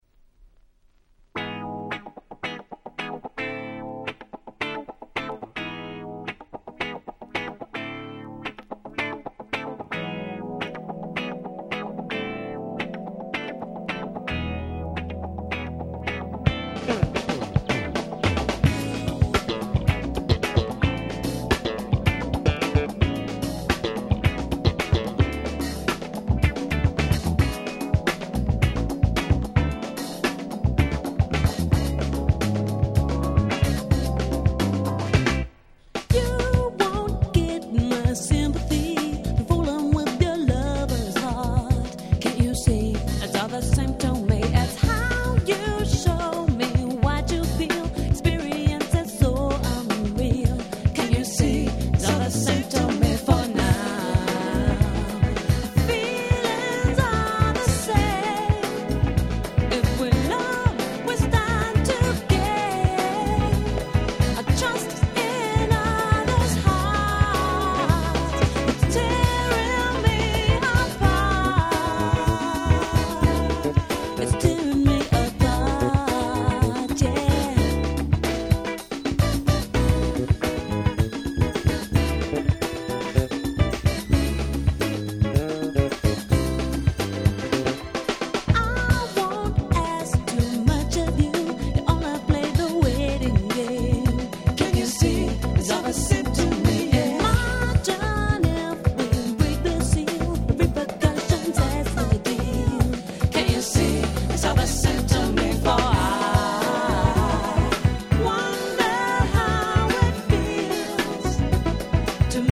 93' Nice UK Soul !!